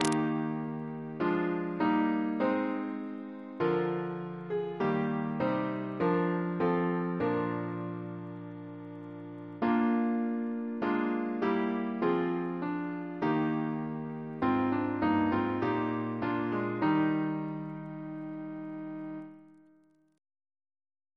CCP: Chant sampler
Double chant in E♭ Composer: Phocion Henley (1728-1764) Reference psalters: ACB: 236; OCB: 149; PP/SNCB: 101